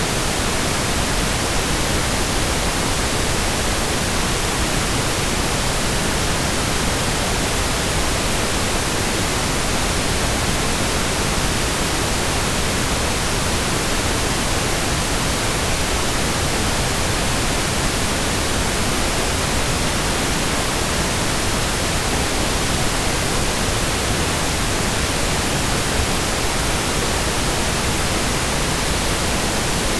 Le bruit rose est généré électroniquement en utilisant des algorithmes spécifiques, et il peut être obtenu en atténuant la puissance du bruit blanc à mesure que la fréquence augmente, de manière à ce qu'il suive la distribution de puissance 1/f souhaitée.
Bruit Rose
Sa distribution d'énergie égale par bande d'octave est souvent utilisée pour équilibrer les réponses en fréquence des équipements audio.
bruitrose.mp3